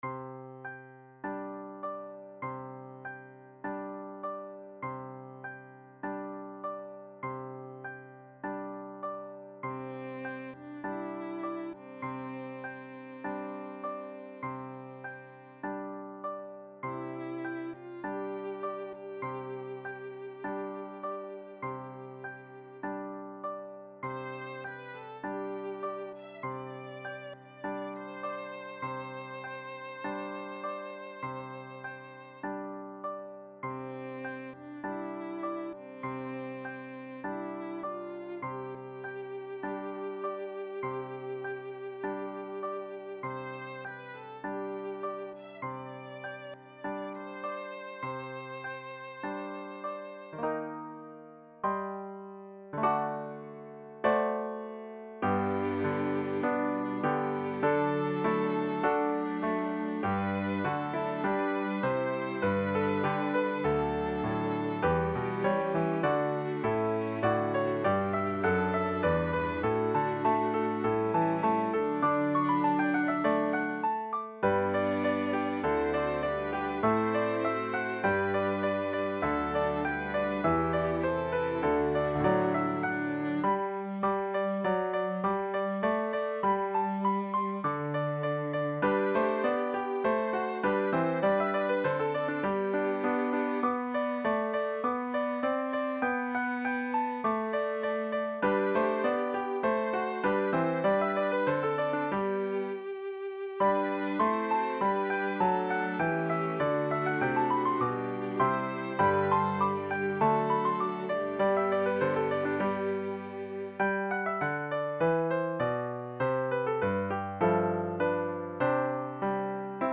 SATB arrangement of 2 LDS hymns and a Primary song